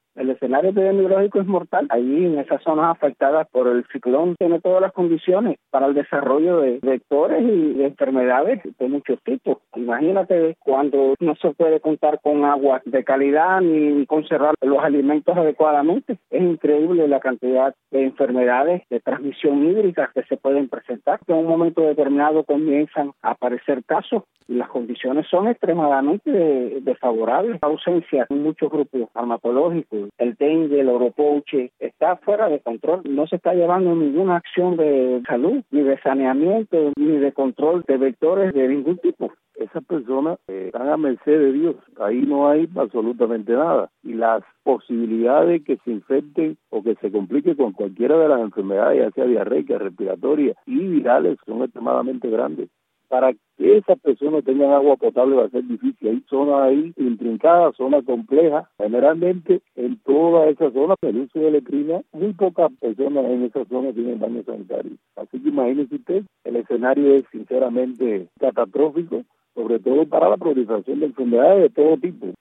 Dos médicos cubanos hablan sobre la emergencia sanitaria en la zona del desastre en Guantánamo